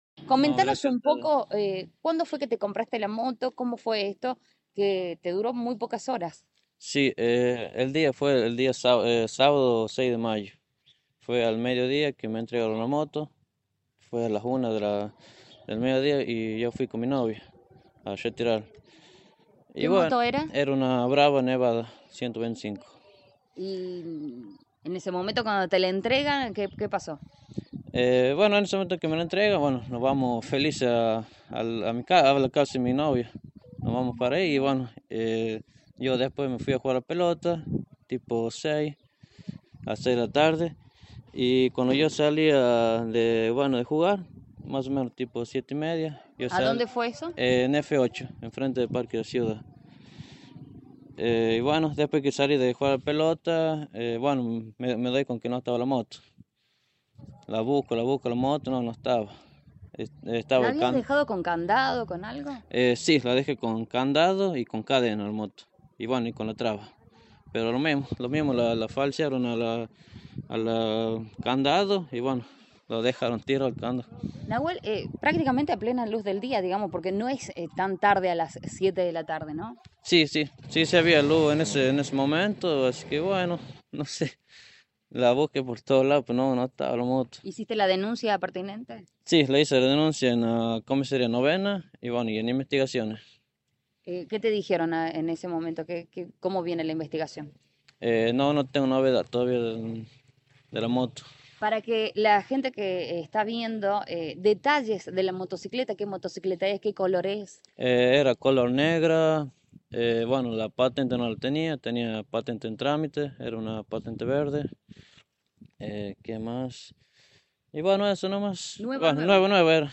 PROPIETARIO DE MOTO ROBADA